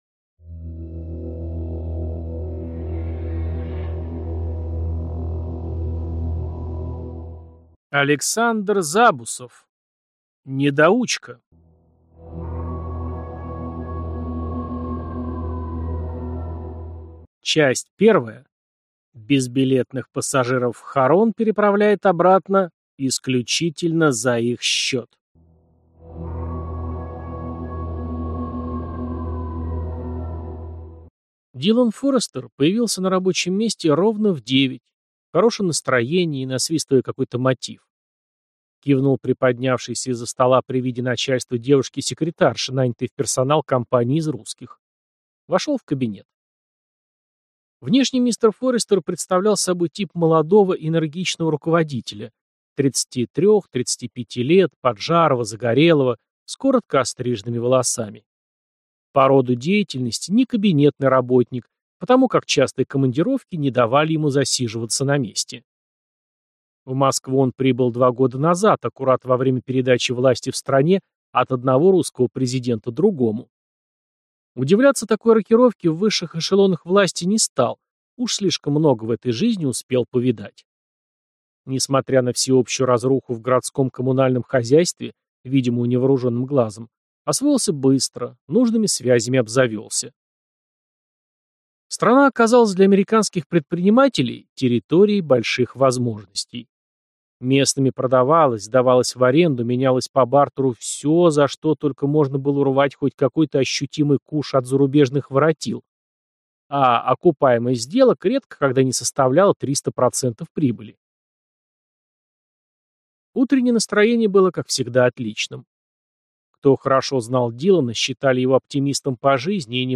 Аудиокнига Недоучка | Библиотека аудиокниг